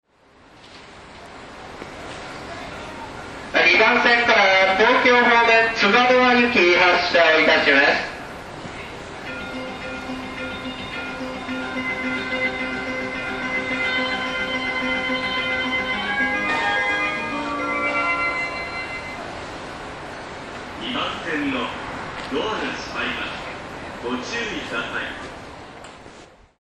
発車メロディーの音量が小さい上に駅員さんが喋りまくるので収録は困難です。
Cielo Estrellado これでもスピーカーの真下です。